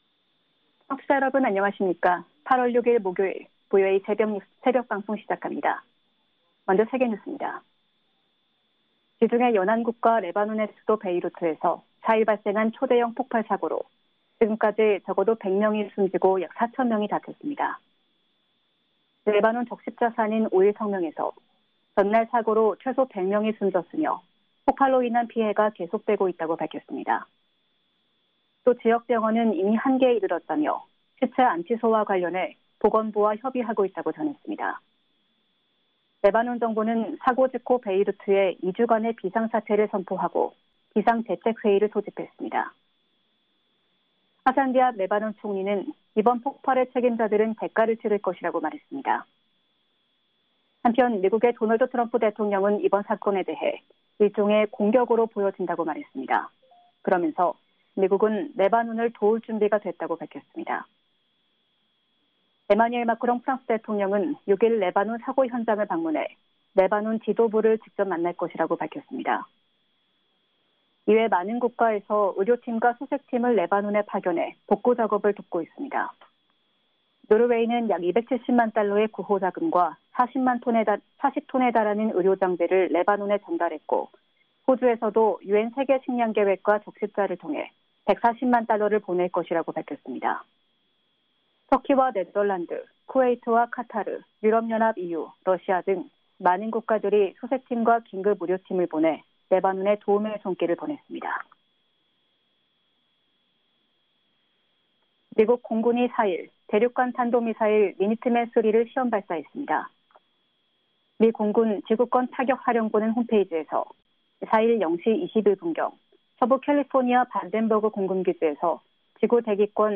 VOA 한국어 '출발 뉴스 쇼', 2020년 8월 6일 방송입니다. 유엔안보리 대북제재위원회 전문가 패널이 최근 제출한 북한의 핵무기 소형화 가능성과 불법 해상 환적 등의 내용이 담긴 중간보고서.